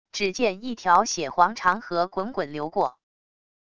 只见一条血黄长河滚滚流过wav音频生成系统WAV Audio Player